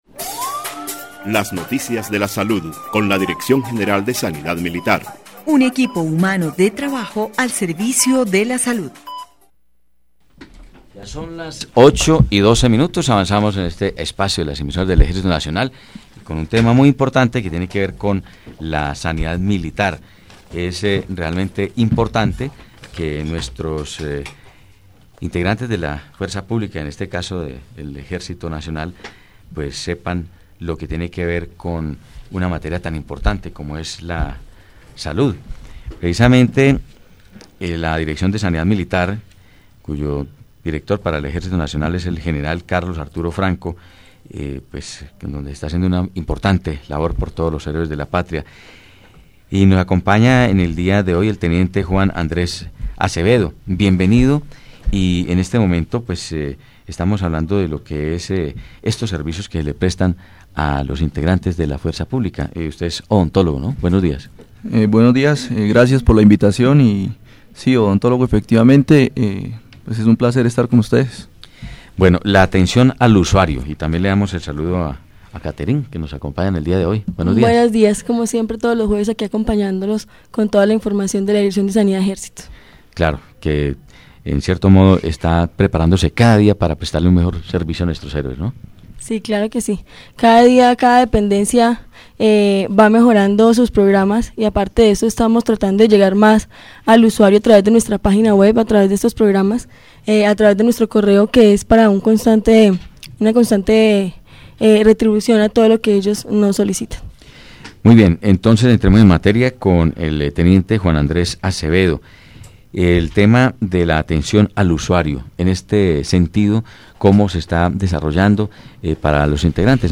Escuche información de la Dirección de Sanidad Ejército en la emisora del Ejército Nacional 93.4 FM, a partir de las 8:00 de la mañana hasta las 8:30 am.